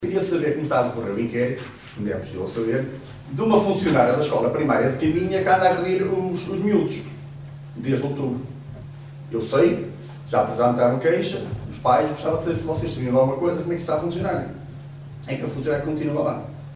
Em março deste ano um pai dirigiu-se ao executivo não para falar de bullying entre alunos, mas sim por causa de uma agressão por parte de uma funcionária da escola EB de Caminha a uma criança, um caso que segundo aquele encarregado de educação resultou num processo.